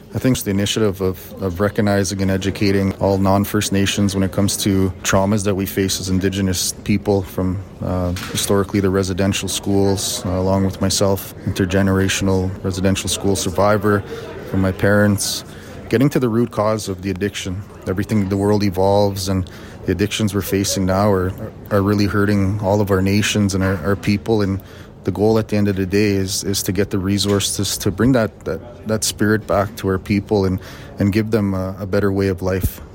Windspeaker Radio Network spoke with Grand Chief of Treaty Six Cody Thomas, as he says it’s all about getting to the root cause of addiction that many houseless Indigenous people face inside the encampments.